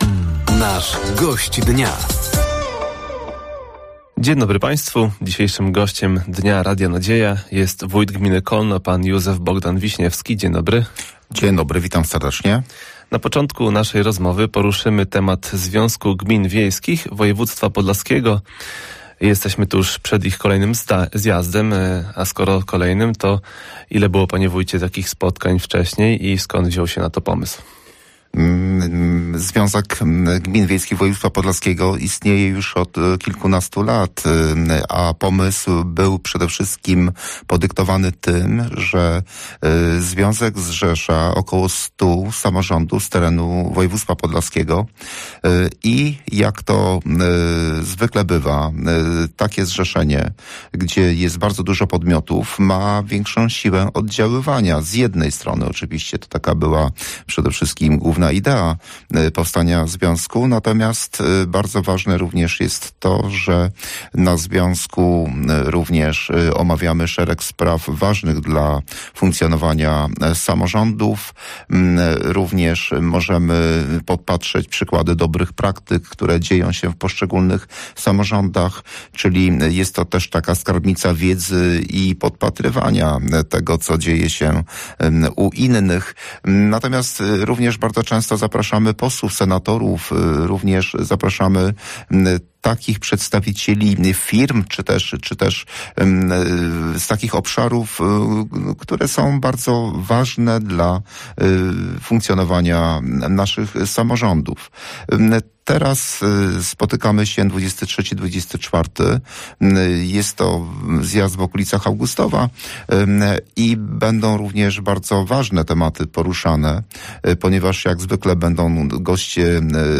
Gościem Dnia Radia Nadzieja był Józef Bogdan Wiśniewski, wójt gminy Kolno. Tematem rozmowy był między innymi Związek Gmin Wiejskich Województwa Podlaskiego, inwestycje w zabytkowe kapliczki i zmiana diet dla sołtysów.